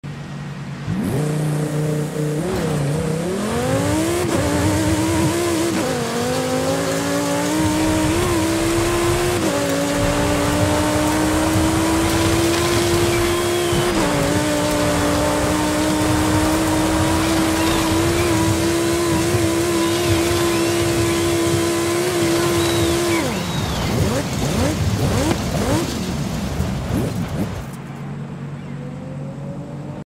2011 Lamborghini Sesto Elemento Off Road Sound Effects Free Download
2011 Lamborghini Sesto Elemento Off-Road